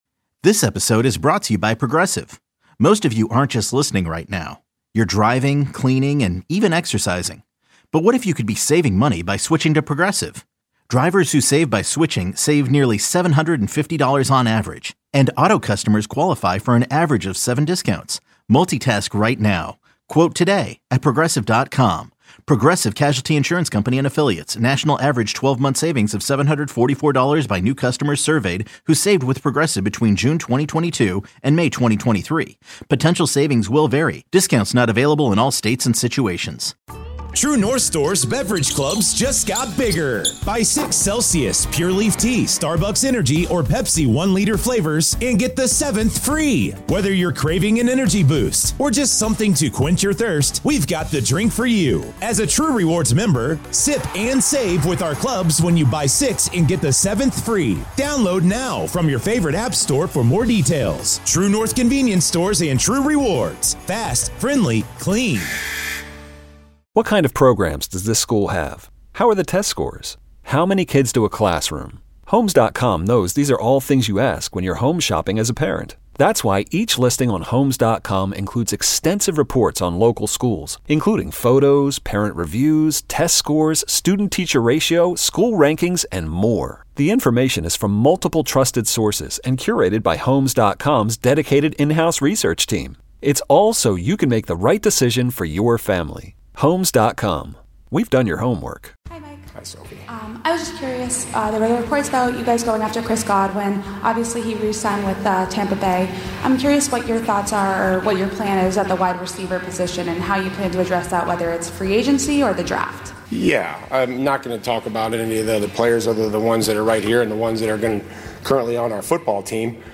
airs live weekdays 10 a.m. - 2 p.m. on WEEI-FM